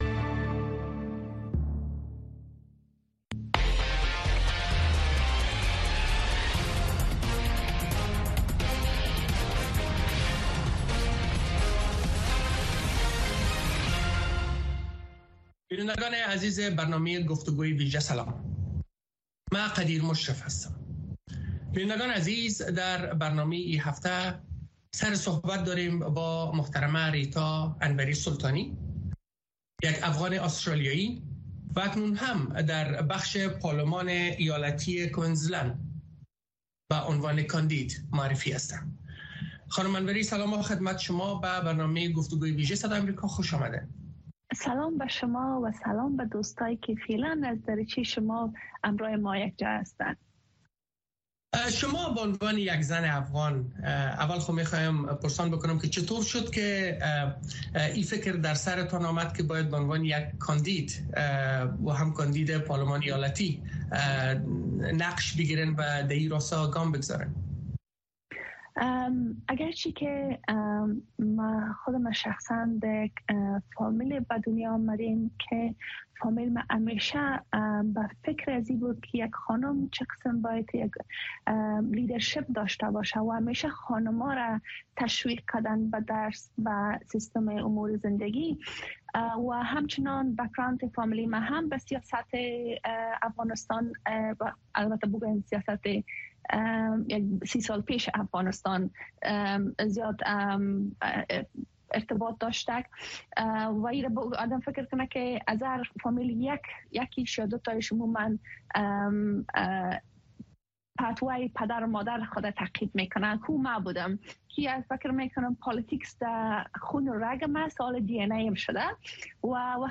گفتگو‌های ویژه با مسوولان، مقام‌ها، کارشناسان و تحلیلگران در مورد مسایل داغ افغانستان و جهان را هر شنبه در نشرات ماهواره‌ای و دیجیتلی صدای امریکا دنبال کنید.